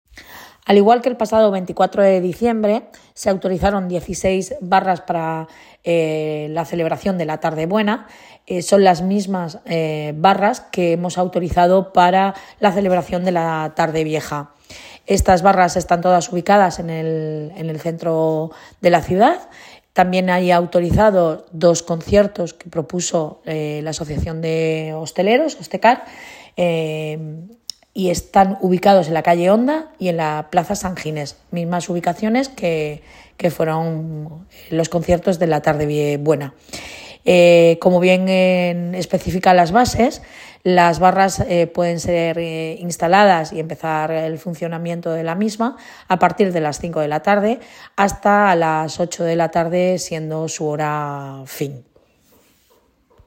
Enlace a Declaraciones de la edil Belén Romero.